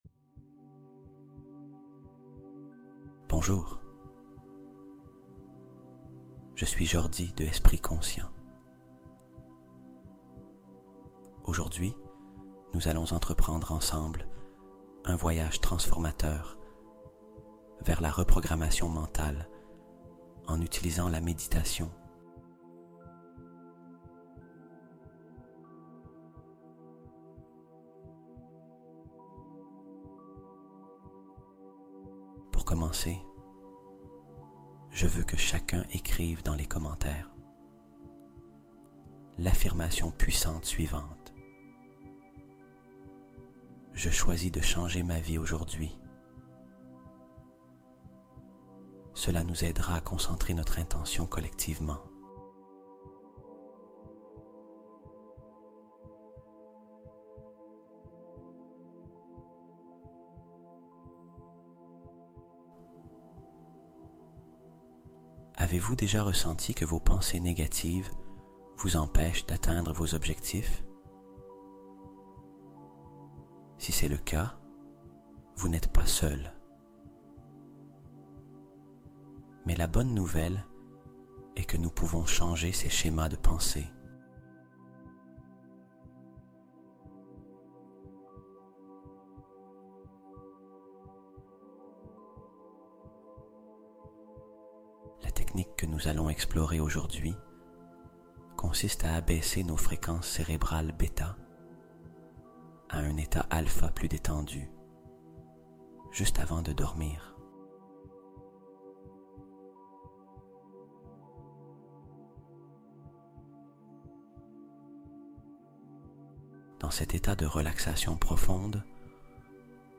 Renouveau Mental : Hypnose de transformation profonde durant le sommeil